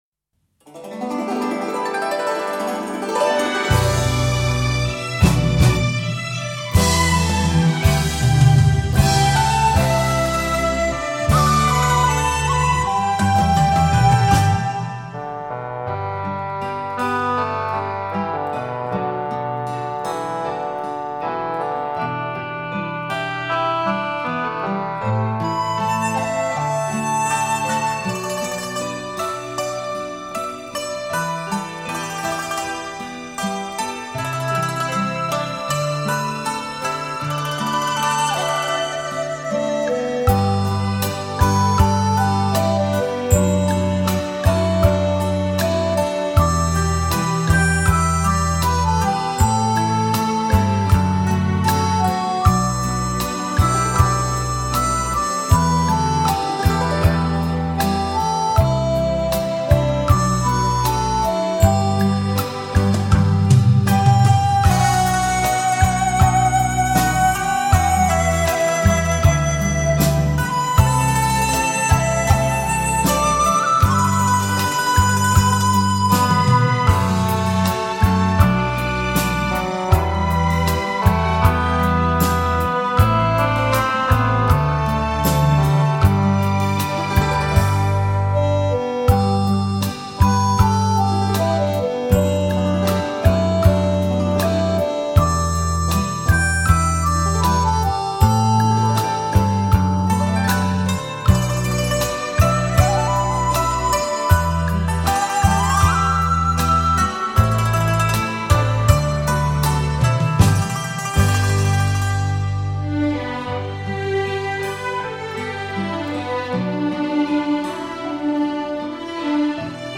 回归传统音乐风潮，演绎中式浪漫情调，尽显中国轻音乐元素的绝色风彩。
HI-FI音响三维环绕高临场音效。